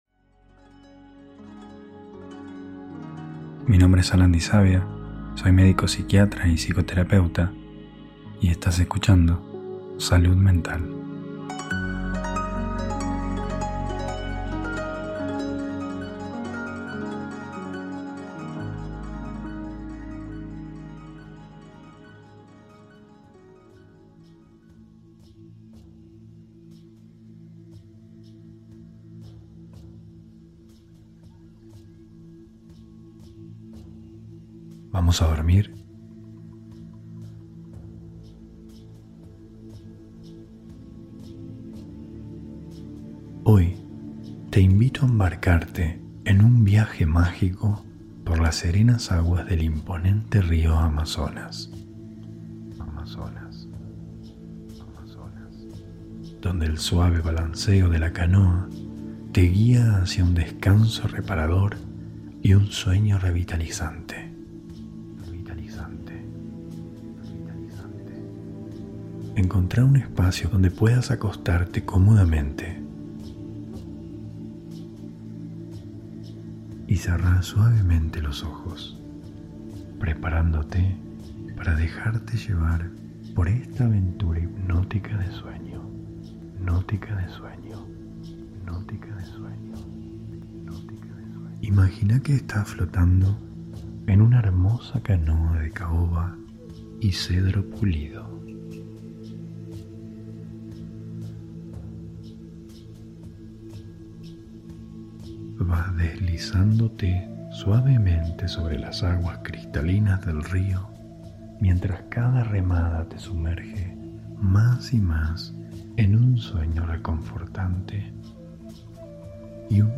Hipnosis para dormir - Marzo 2024 ✨
Hipnosis guiada para dormir.